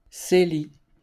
Cély (French pronunciation: [seli]